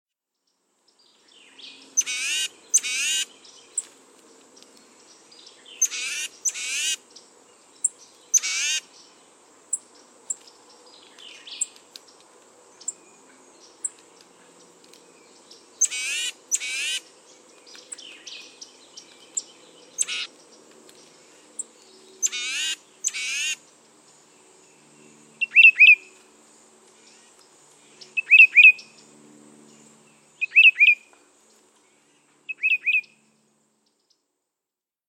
Tufted Titmouse
Bird Sound
Titmouse calls are nasal and mechanical. A scratchy, chickadee-like tsee-day-day-day is the most common. Tufted Titmice also give fussy, scolding call notes and, when predators are sighted, a harsh distress call that warns other titmice of the danger.